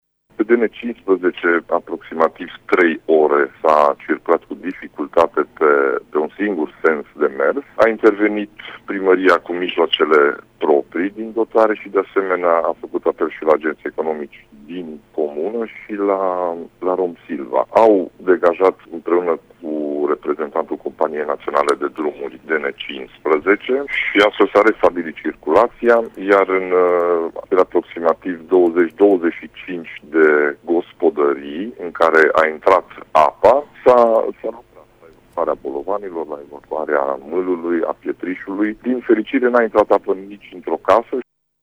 Preşedintele Consiliului Judeţean Mureş, Ciprian Dobre, a arătat că circulaţia pe DN 15 a fost îngreunată timp de 3 ore şi că 25 de gospodării au fost afectate: